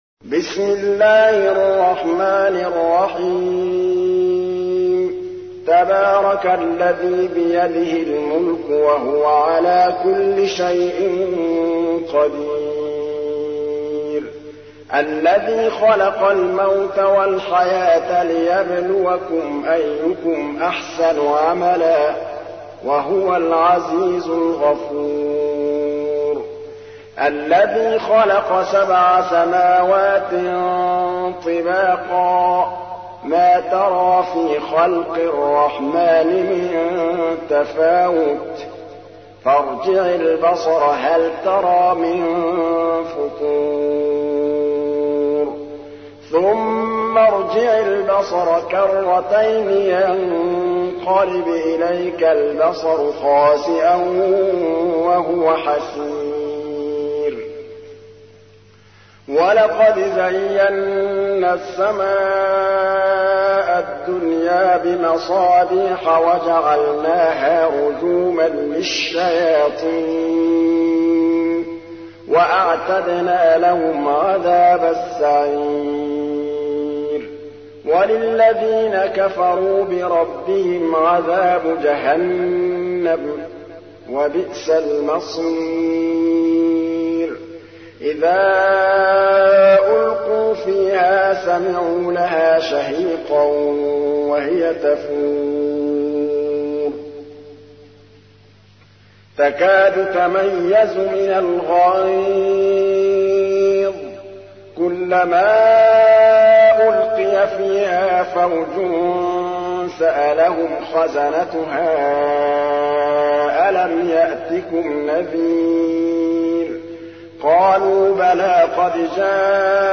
تحميل : 67. سورة الملك / القارئ محمود الطبلاوي / القرآن الكريم / موقع يا حسين